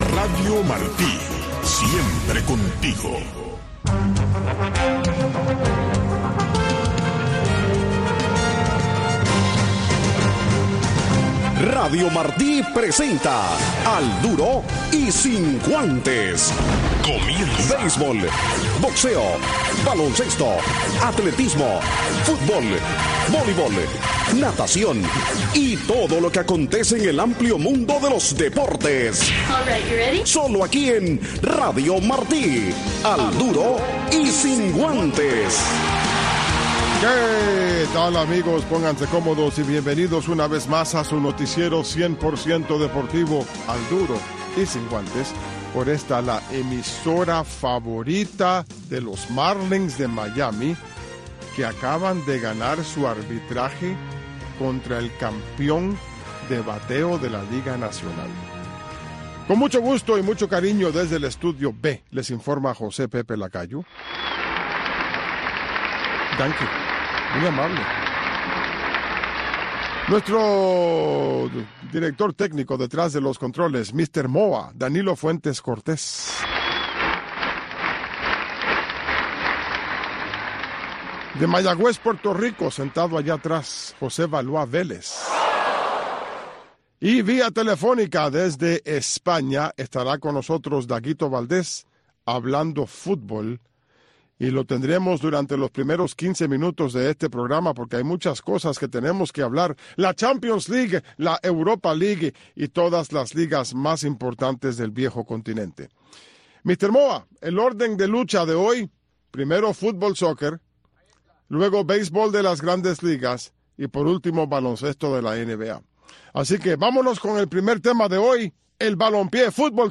Un resumen deportivo en 60 minutos conducido por